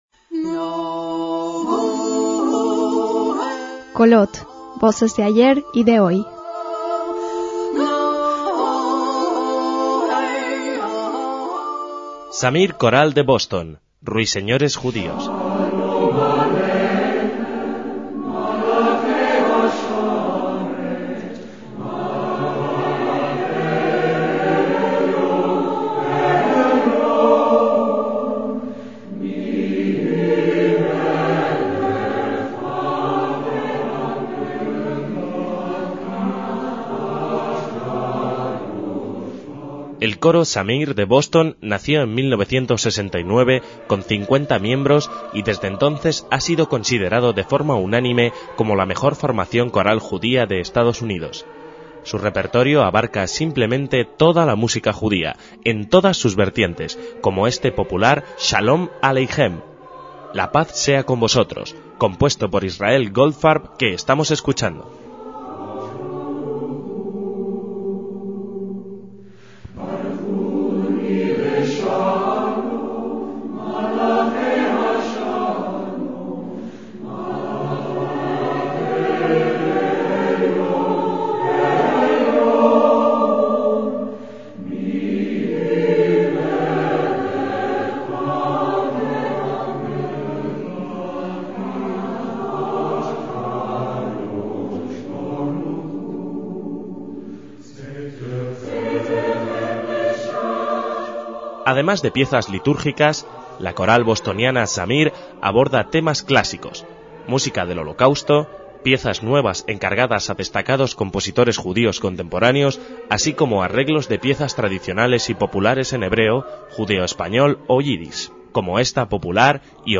se compone de 50 voces con un repertorio amplísimo en el tiempo y los diferentes idiomas judíos.